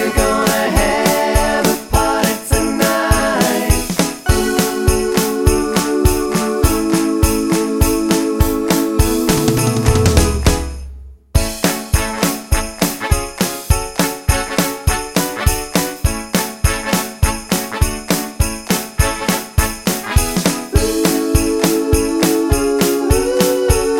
No Bass Pop (1980s) 3:41 Buy £1.50